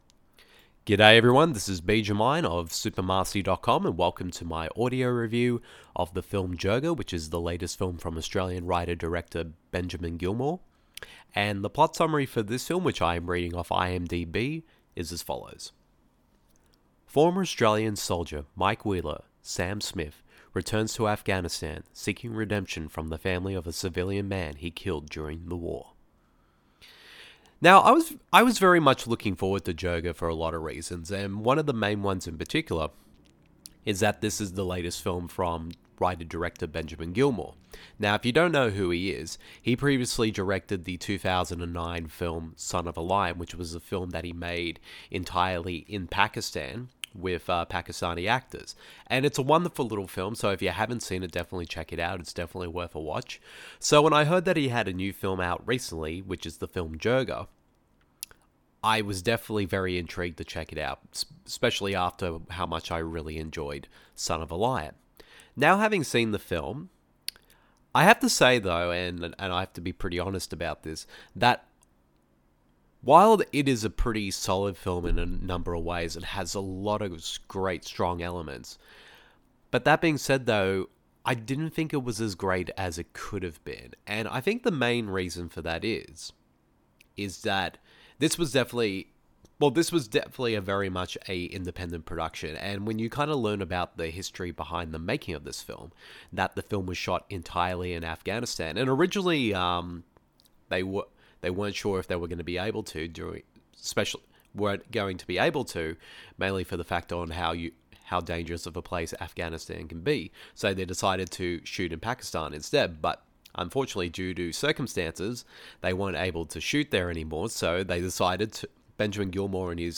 After a detour to documentary genre with the 2012 PARAMEDICO, Gilomour is back with his long waited narrative follow-up with JIRGA, a film about one Australian soldier’s journey to for forgiveness from an that was shot and set entirely in Afghanistan. The following review of the film is in an audio format.